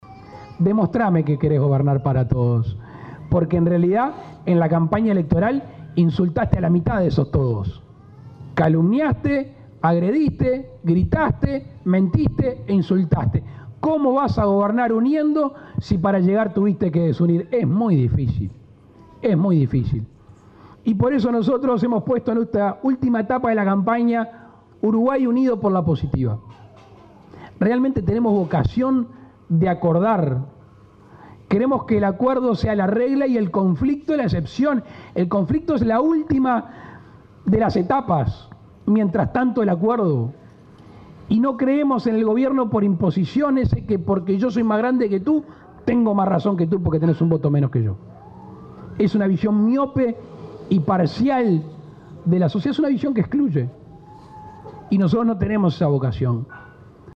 En Rivera, Lacalle Pou le preguntó a Tabaré Vázquez, sin nombrarlo, ¿cómo gobernará?, si es que le toca triunfar.